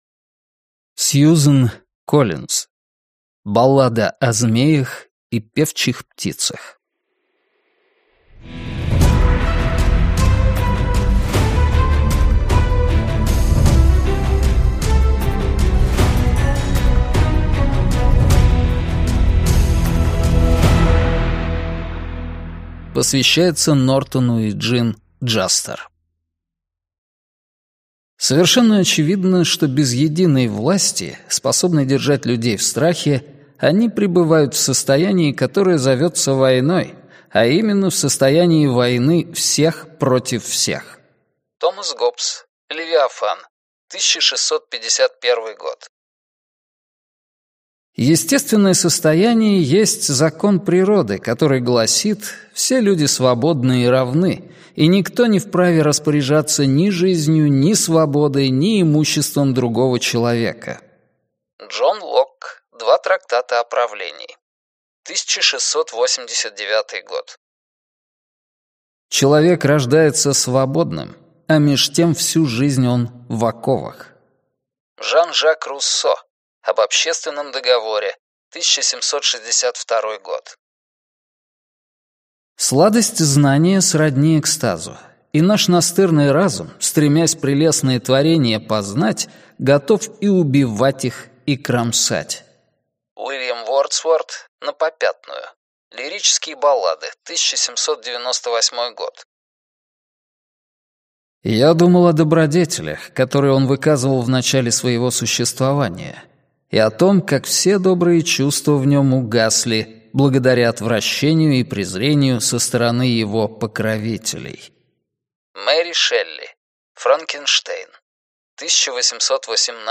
Аудиокнига Баллада о змеях и певчих птицах | Библиотека аудиокниг